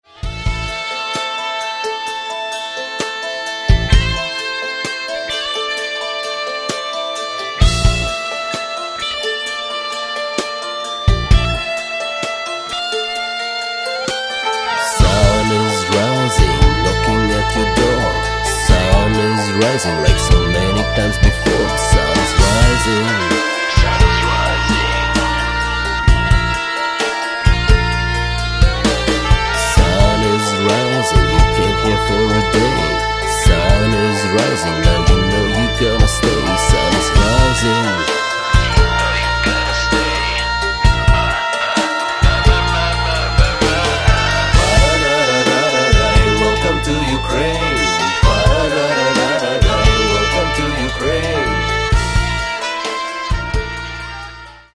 Каталог -> Рок та альтернатива -> Фолк рок